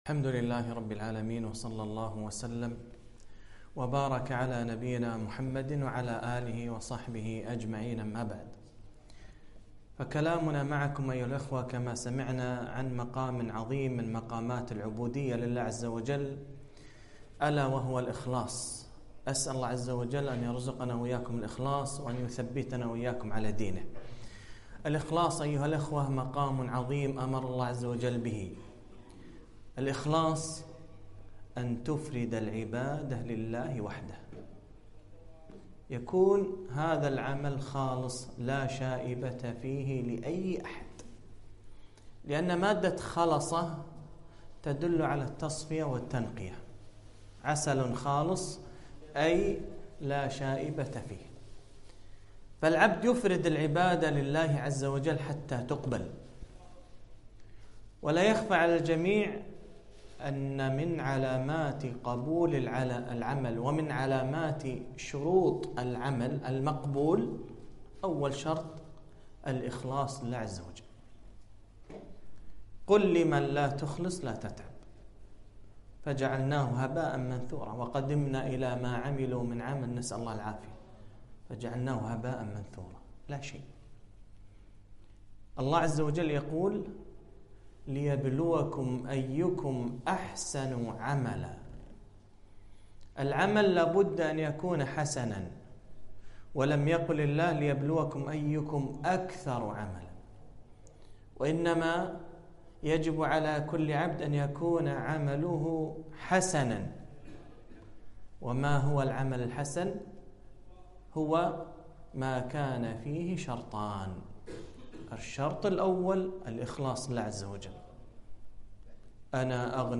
محاضرة - ( الإخلاص )